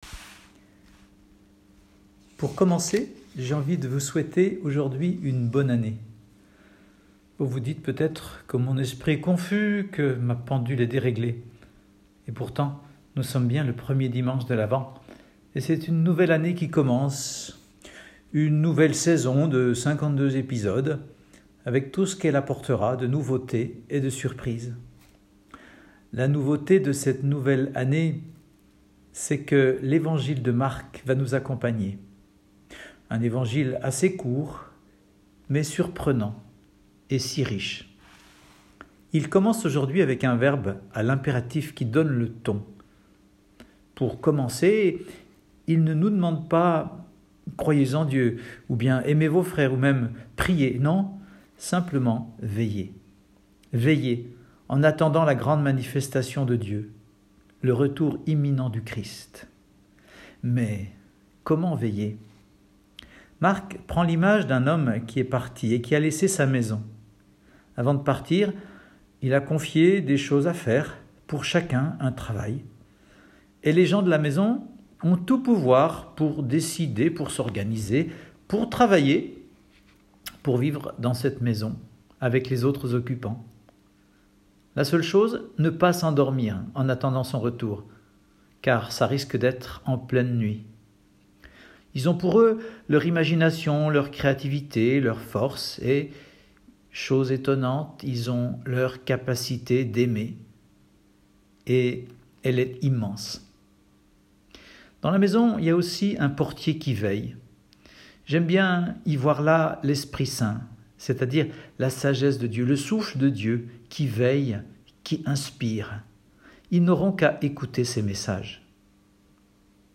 Retrouver l'homélie